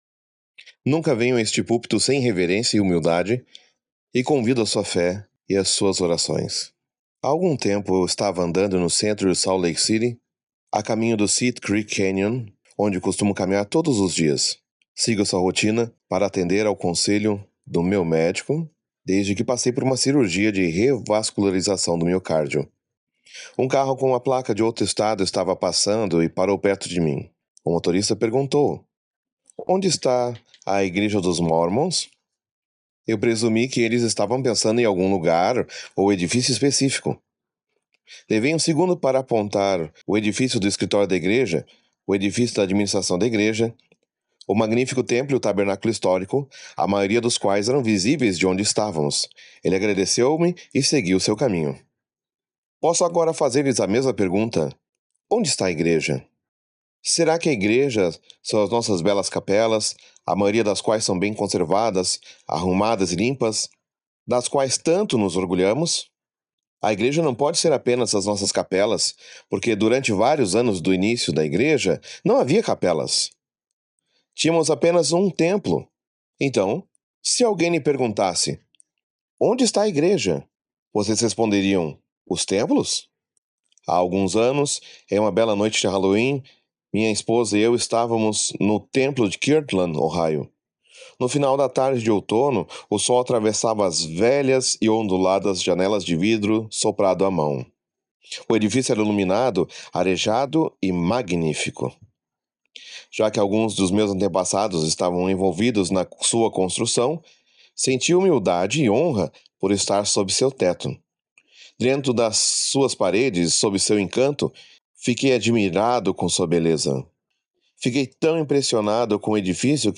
Audio recording of Onde está a Igreja? by James E. Faust